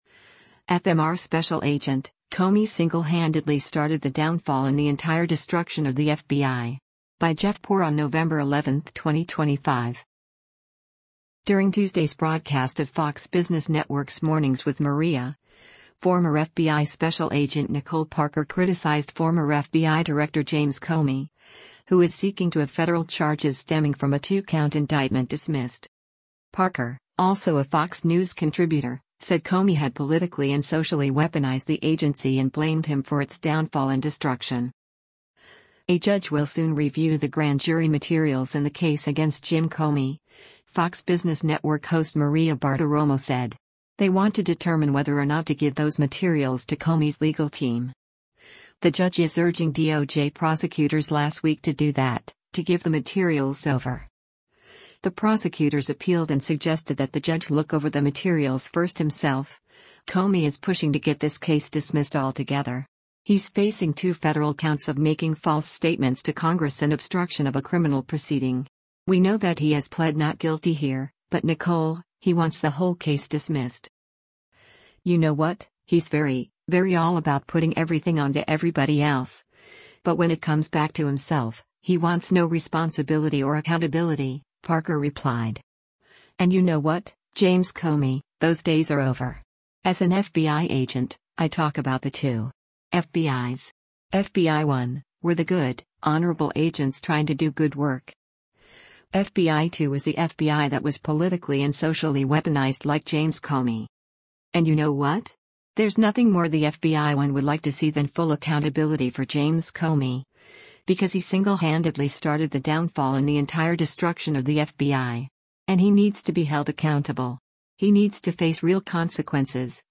During Tuesday’s broadcast of Fox Business Network’s “Mornings with Maria,”